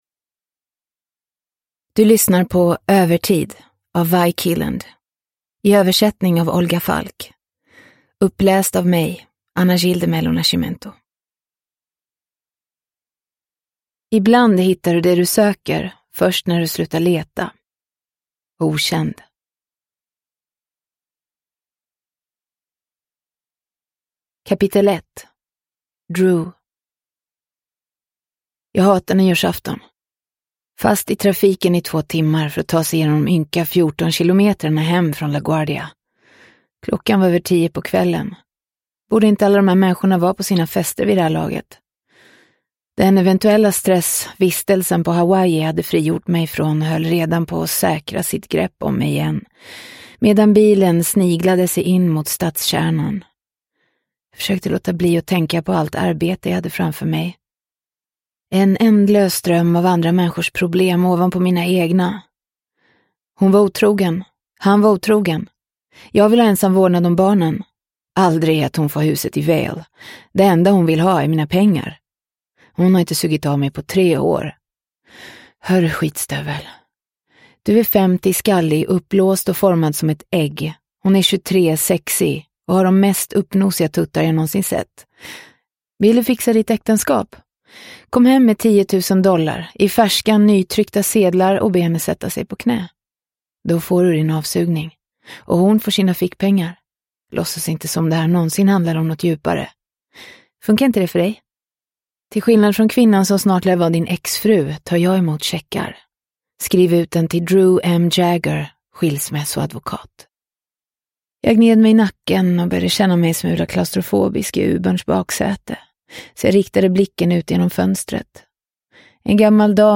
Övertid – Ljudbok – Laddas ner